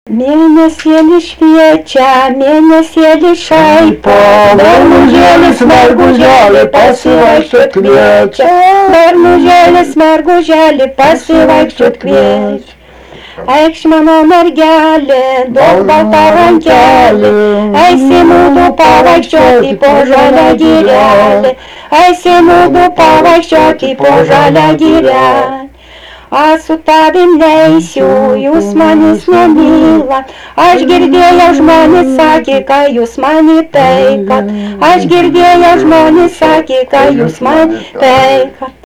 daina
Erdvinė aprėptis Juciai
Atlikimo pubūdis vokalinis
Pastabos 2 balsai